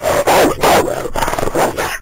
wolf.ogg